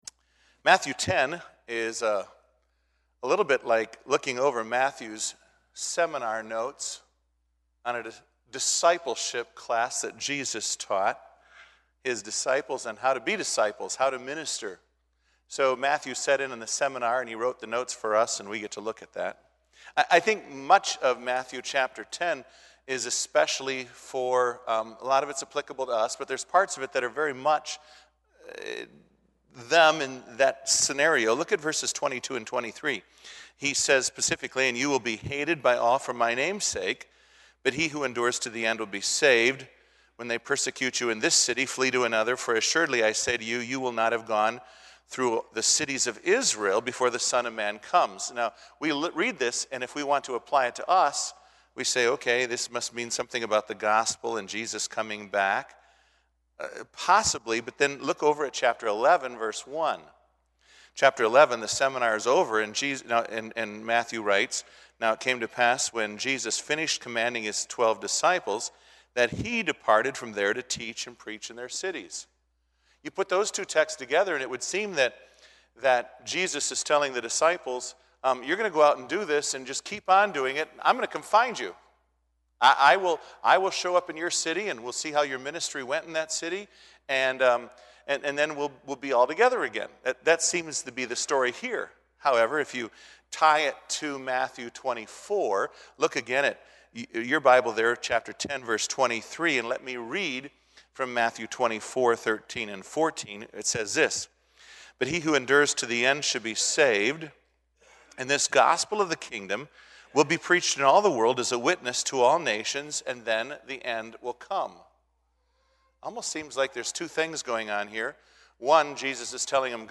2017 Bible Conference